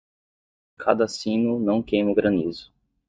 Pronounced as (IPA)
/ˈkej.mɐ/